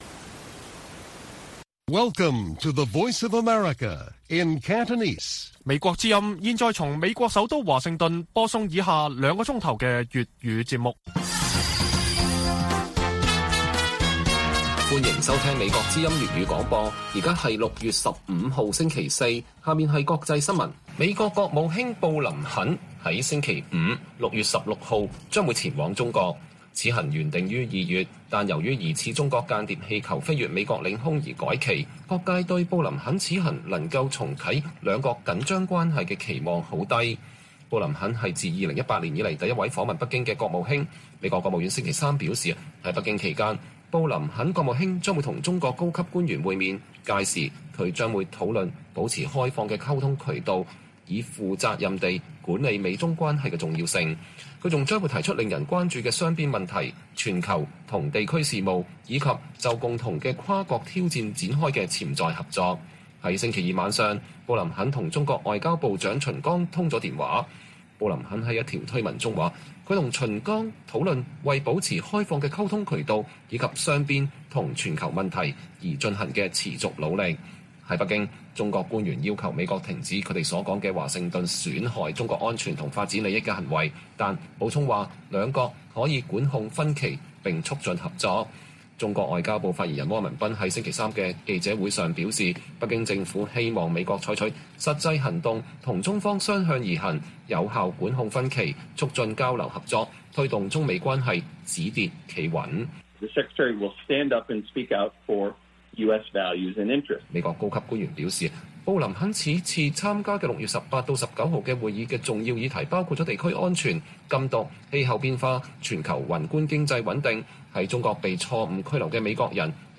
粵語新聞 晚上9-10點: 美國務院官員指布林肯訪華將坦誠交流台海情勢